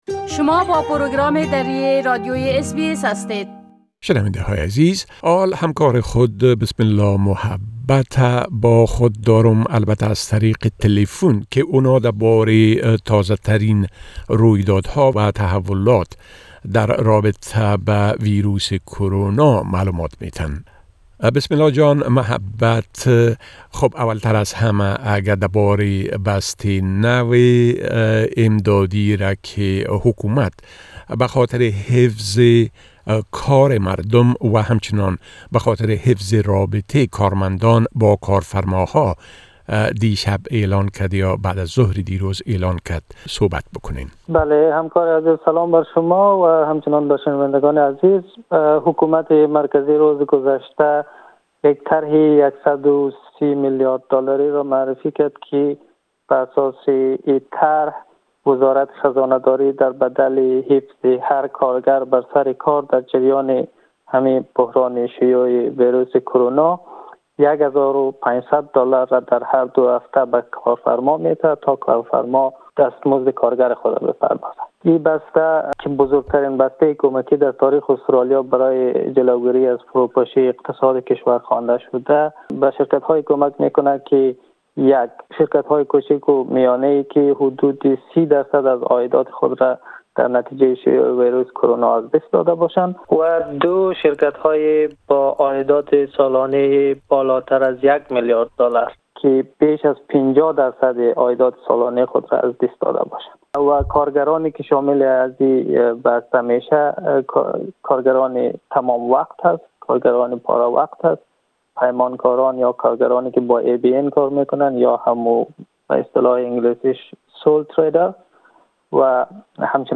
در این گفت‌وگو: